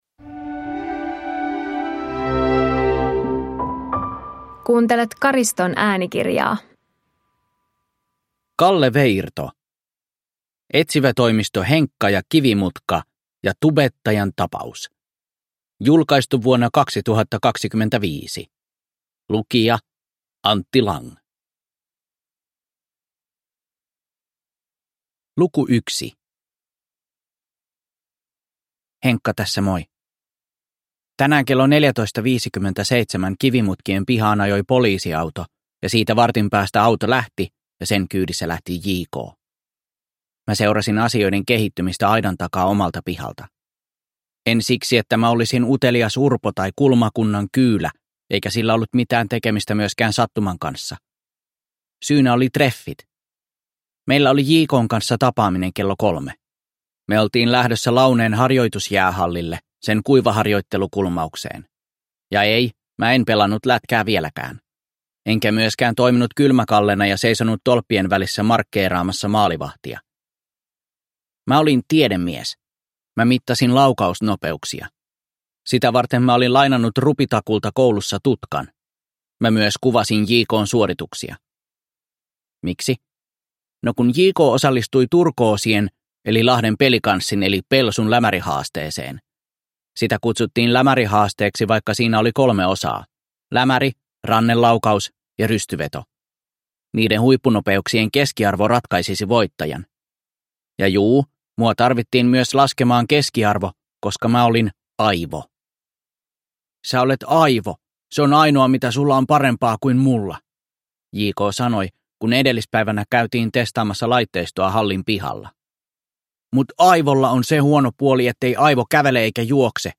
Etsivätoimisto Henkka & Kivimutka ja tubettajan tapaus (ljudbok) av Kalle Veirto